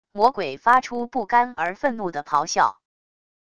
魔鬼发出不甘而愤怒的咆哮wav音频